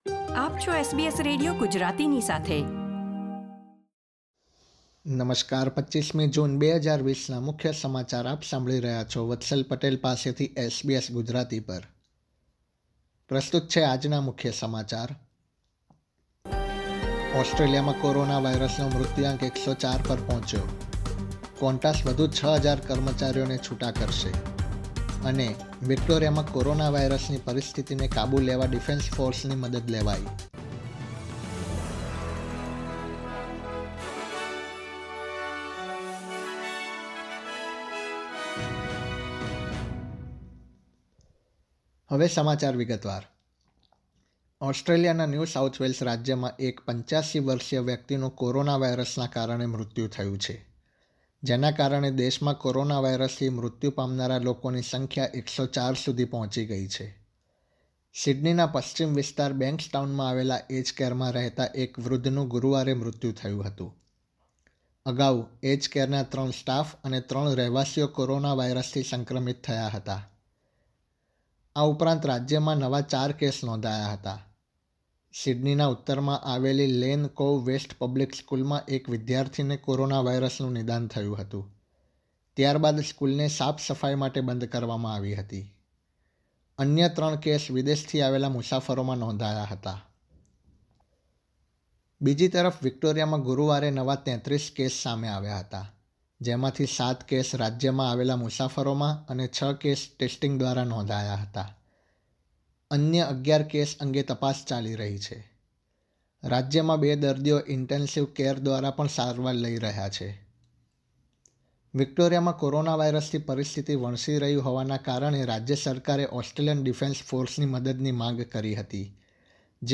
SBS Gujarati News Bulletin 25 June 2020